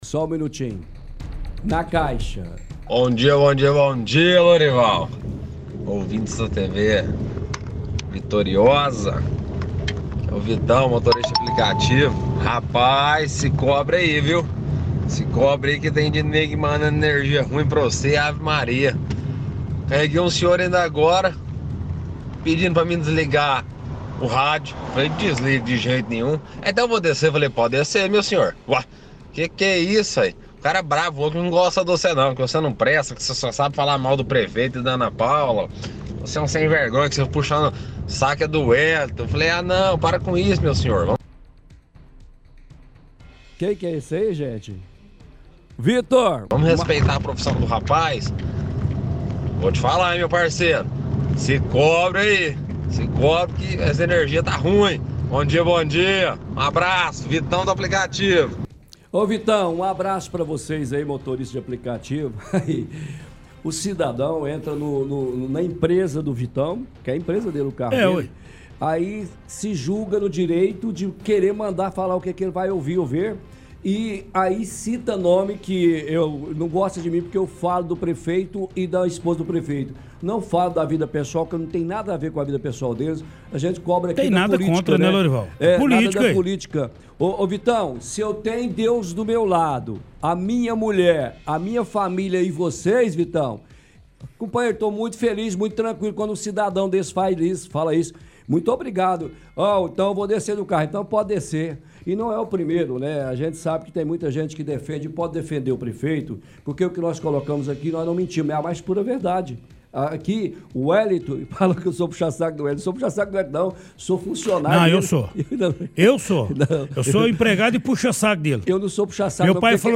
– Transmissão de áudio de ouvinte, motorista de aplicativo, dizendo que passageiro desceu do carro ao reclamar do programa, que só fala mal do prefeito.